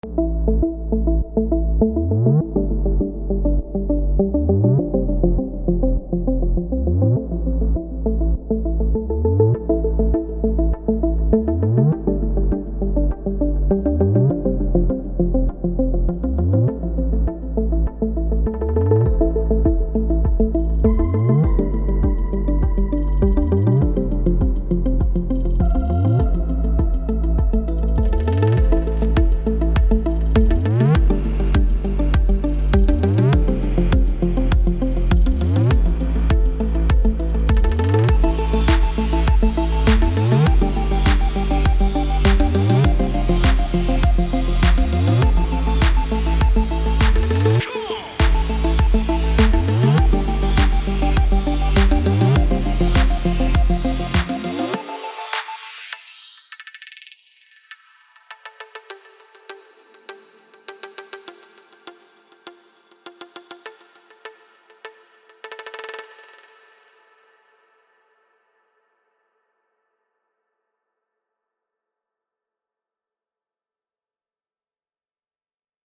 מוזיקת האוס
הקיק חזק מידי תטפל בזה, ואני חושב שזה סאונד קיק פחות מתאים לסגנון אז הייתי מחליף.